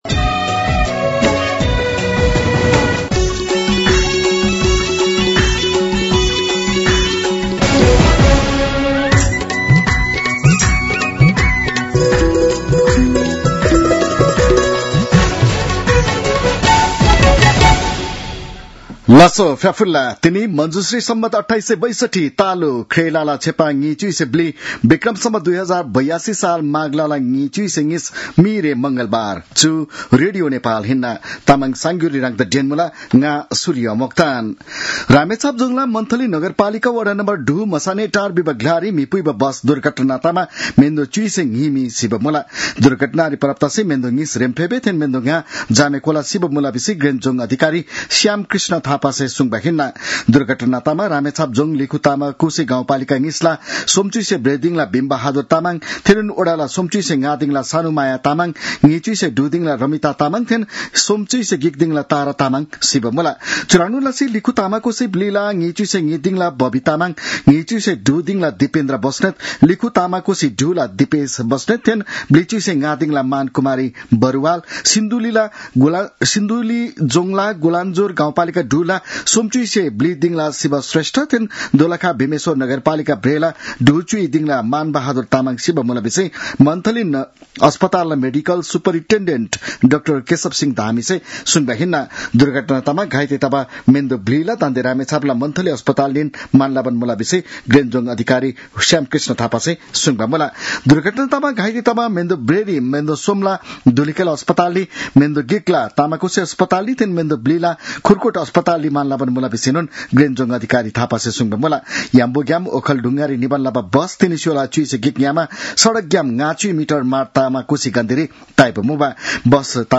तामाङ भाषाको समाचार : २७ माघ , २०८२